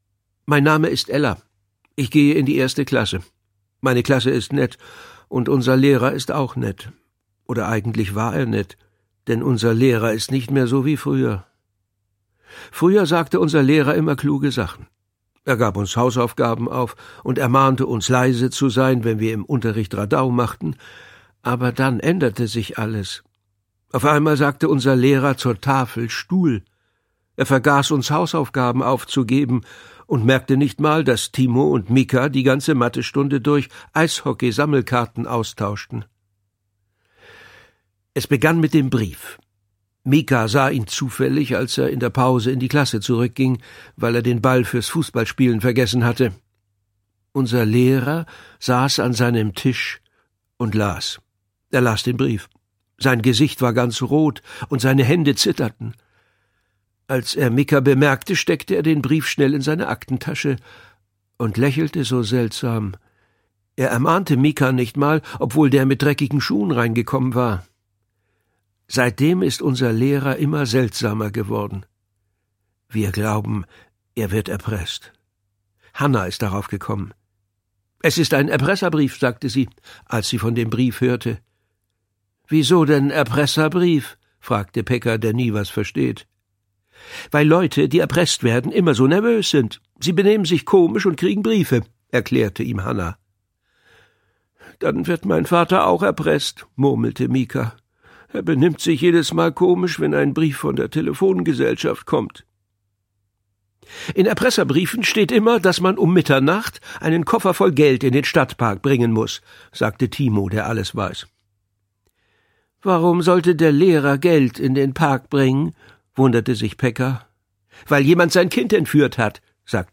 Hörbuch: Ella 1.